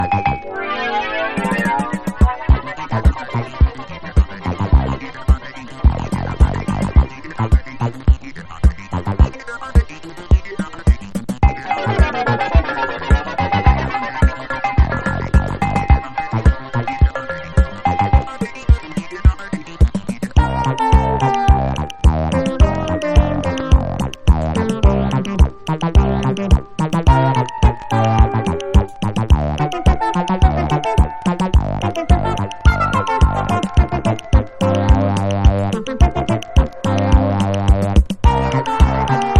ボンゴがフィーチャーされた珍しい現代音楽！
ボンゴという打楽器が秘める無限の可能性と、切っても切ってもボンゴな瞬間が折り重なる大曲！